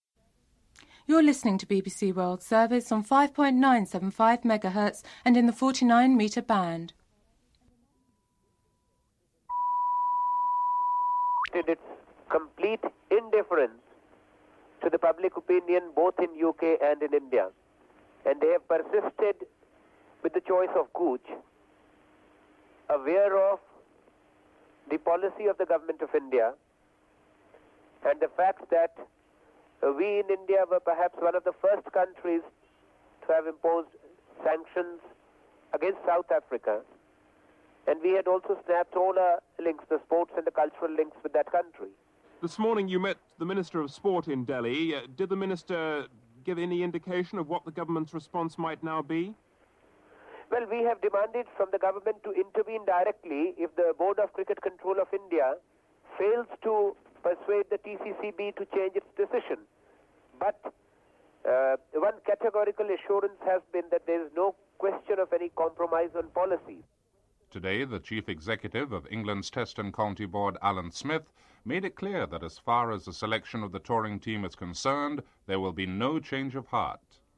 No headlines. No audio from 00:06-00:15.